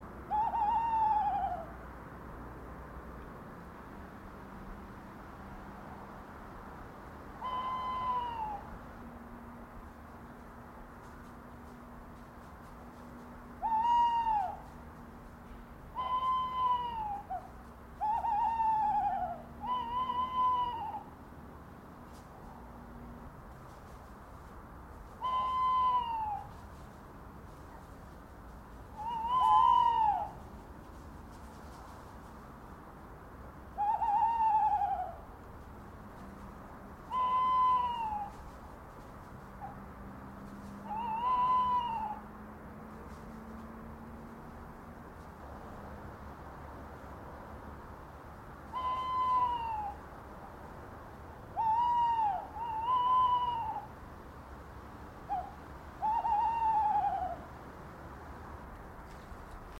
Tawny owls recorded outside my house...